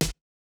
Snare (Pharrell-perc).wav